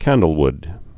(kăndl-wd)